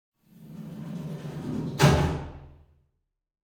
DoorClose.ogg